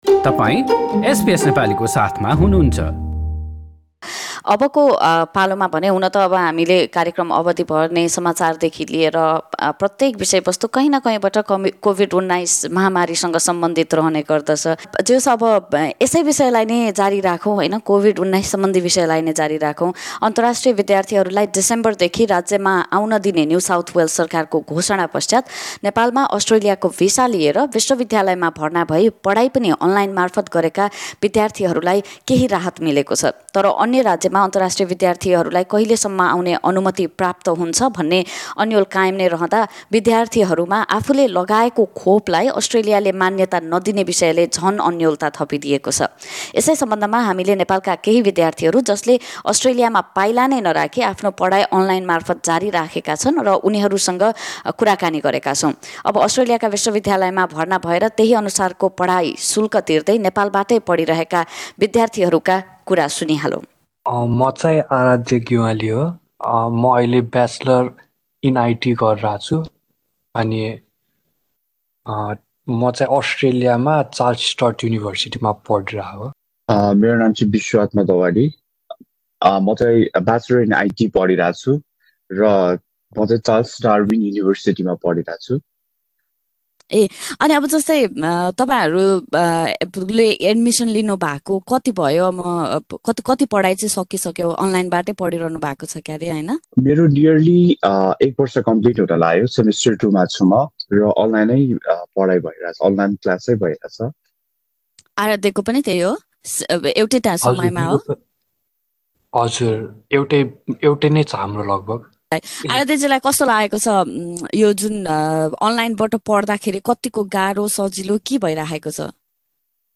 अस्ट्रेलियाको भिसा भए तापनि कोभिड-१९ प्रतिबन्धहरूका कारण विश्वविद्यालयको आफ्नो पढाइ पनि अनलाइन मार्फत गरेका विद्यार्थीहरूलाई सीमा र यात्रा बारे पछिल्ला केही सरकारी निर्णयहरूका कारण केही राहत मिलेको छ। यसै सम्बन्धमा अस्ट्रेलियामा खुट्टा नै नराखी आफ्नो पढाइ अनलाइन गर्दै आएका नेपाल स्थित केही विद्यार्थीसँग हामीले गरेको कुराकानी ।